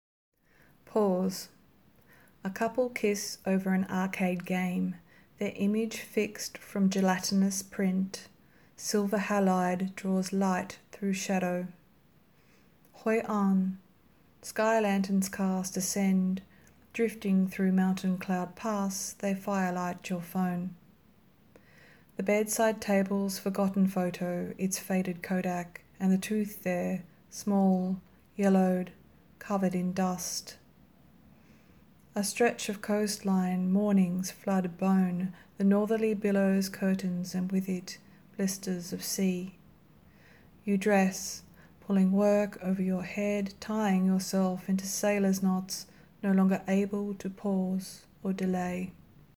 We put out a call to all poets in Issue to 6 to send us audio recordings of their poem and a number of poets did.